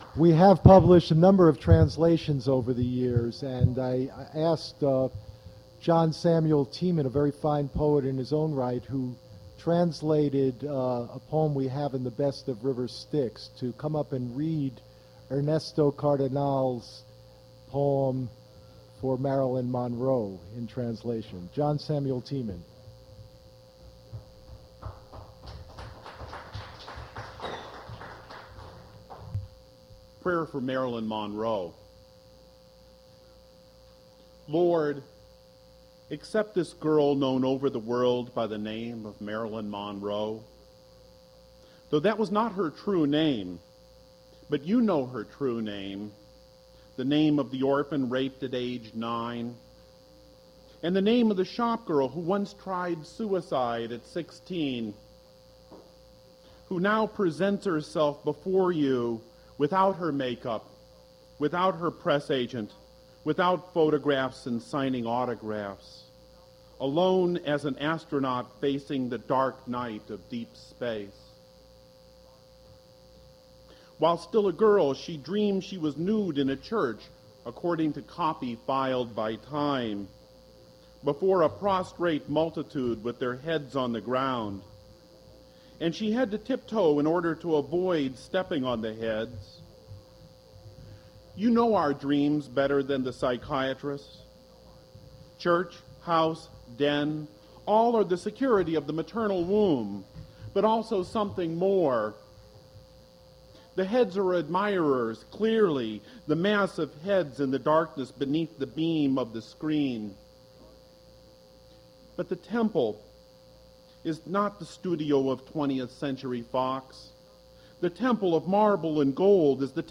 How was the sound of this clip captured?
• mp3 edited access file was created from unedited access file which was sourced from preservation WAV file that was generated from original audio cassette. • River Styx at Duff's • recodings starts with introduction